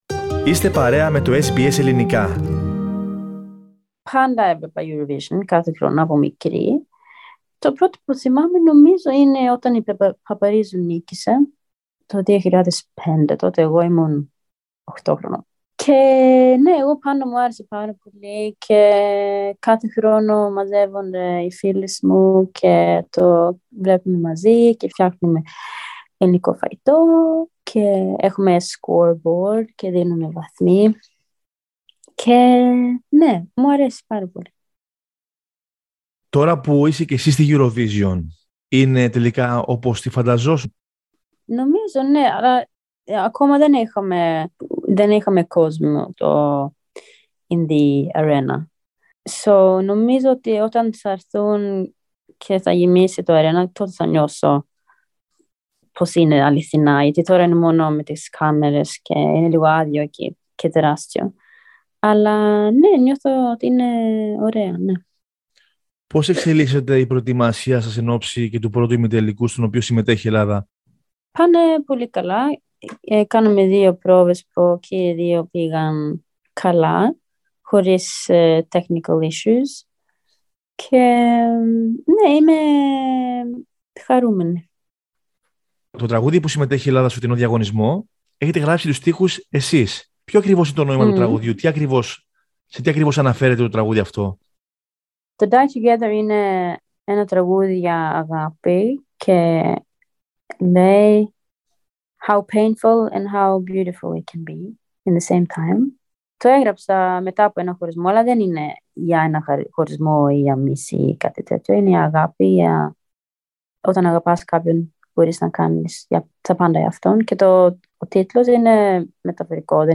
Όπως παραδέχεται και η ίδια σε συνέντευξή της στο Ελληνικό Πρόγραμμα της Ραδιοφωνίας SBS, το τραγούδι αυτό γράφτηκε μετά από έναν χωρισμό της, ωστόσο μιλάει για την αληθινή αγάπη.